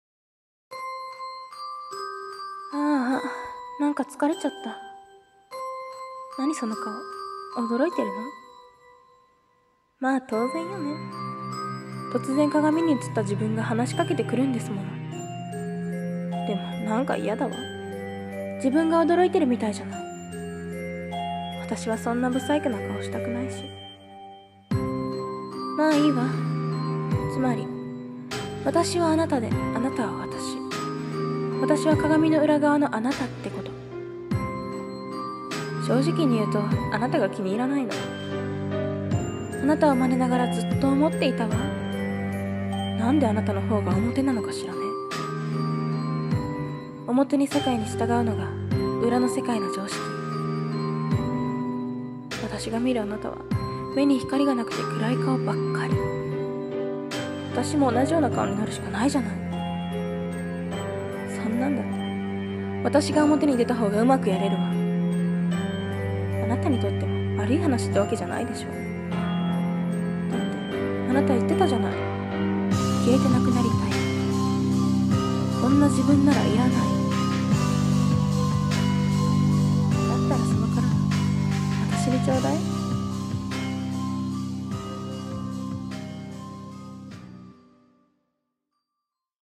声劇
(女性ver.)